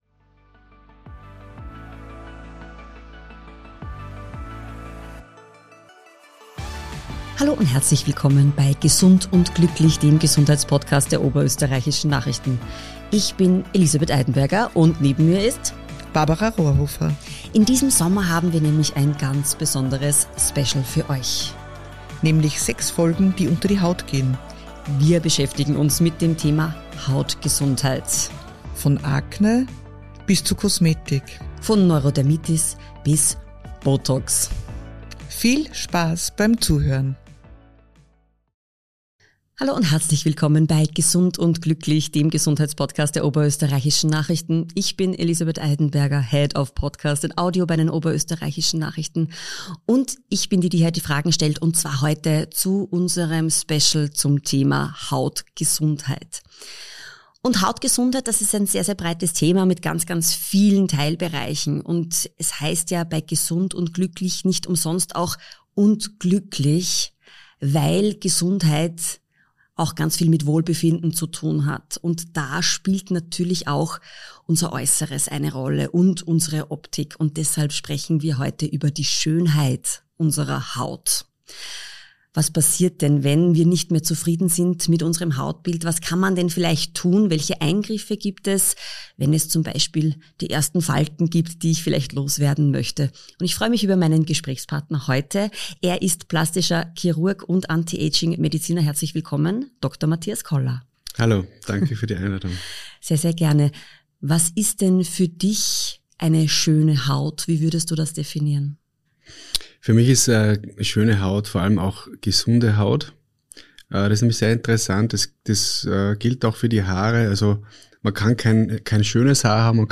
🎙 Jede Woche sprechen sie mit führenden Expertinnen und Experten aus Medizin, Ernährung, Sport, Psychologie und Prävention – kompetent, verständlich und praxisnah.